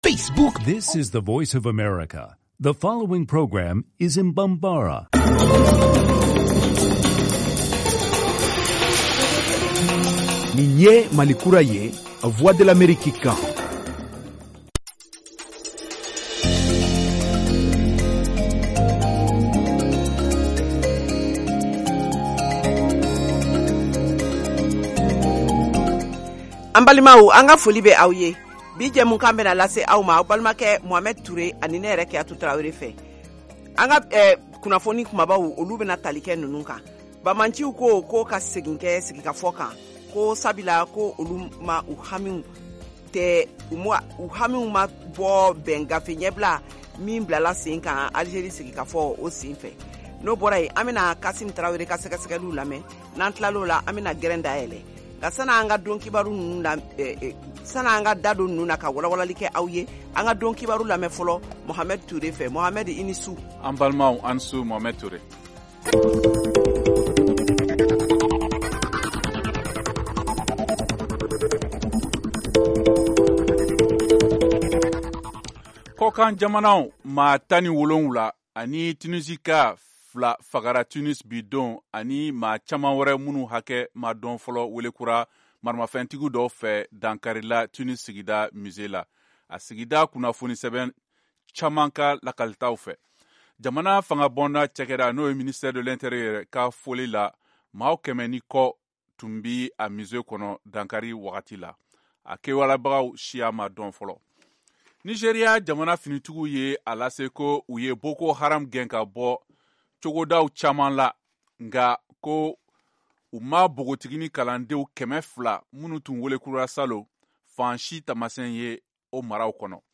Emission quotidienne en langue bambara
en direct de Washington, DC, aux USA.